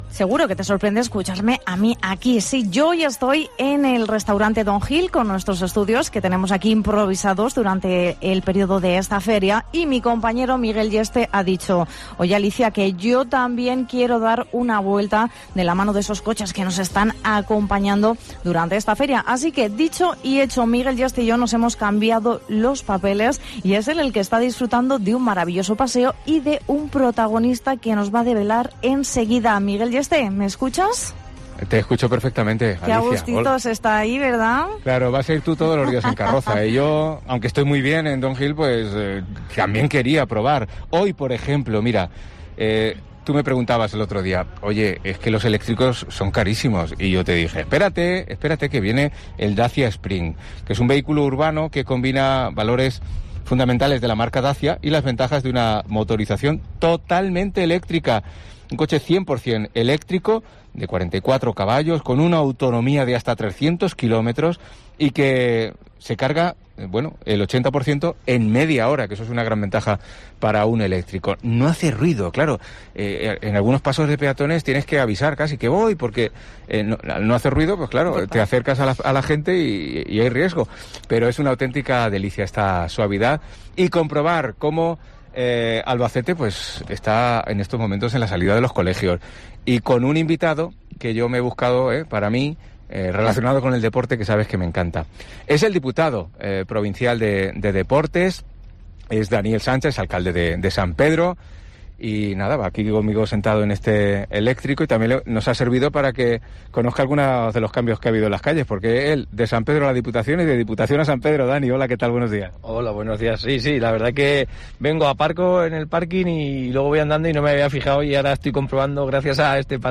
AUDIO: Charlamos a bordo de un Spring, el eléctrico 100x100 de Dacia, con el diputado de Deportes y alcalde de San Pedro, Daniel Sancha.